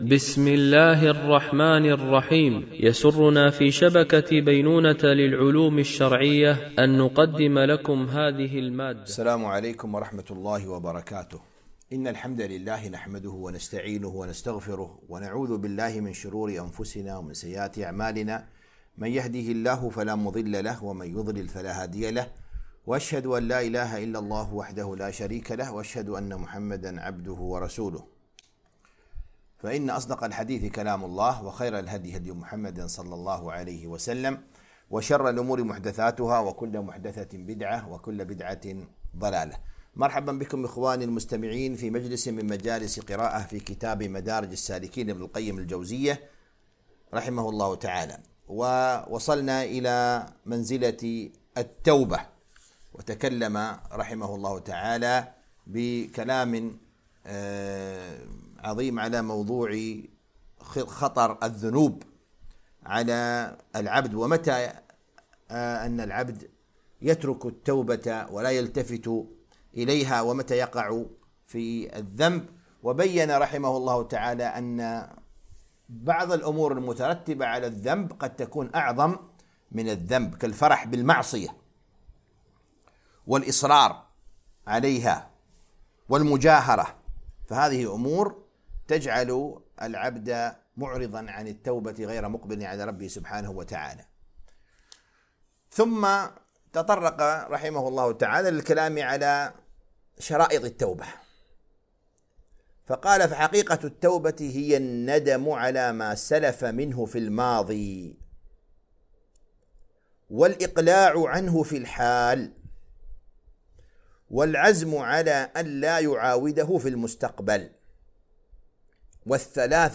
قراءة من كتاب مدارج السالكين - الدرس 24
MP3 Mono 44kHz 96Kbps (VBR)